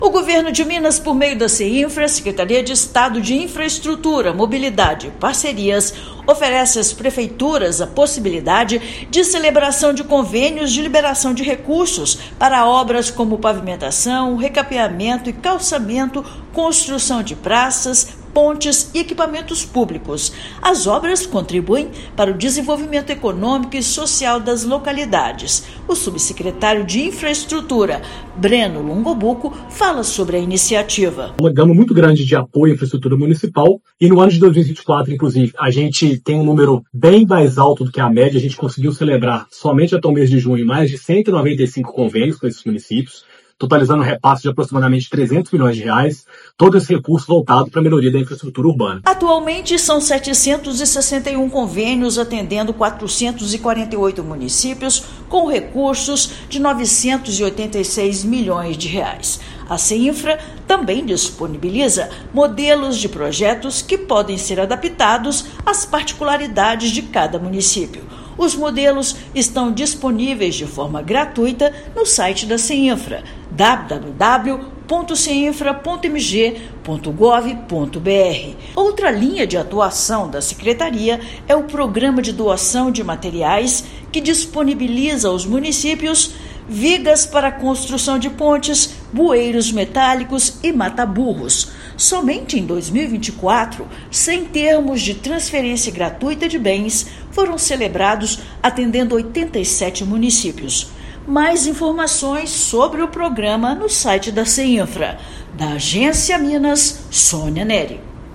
Em 2024 programa atingiu um dos maiores volumes de transferência gratuita de materiais e convênios para a realização de obras de infraestrutura. Ouça matéria de rádio.